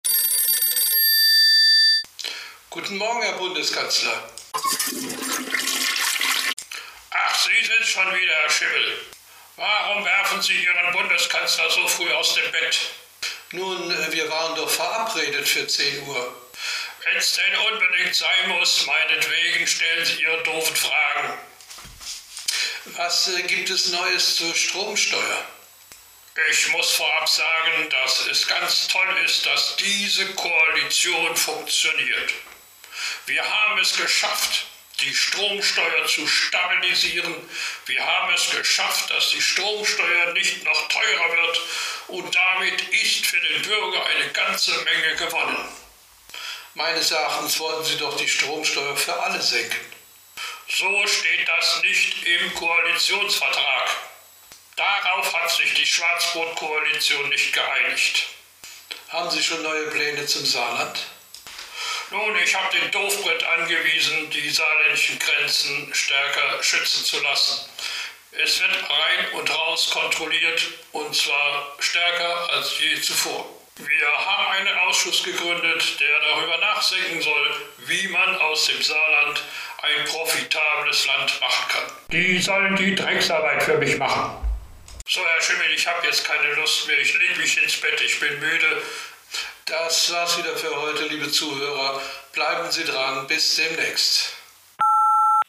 Merz Interview - Stromsteuer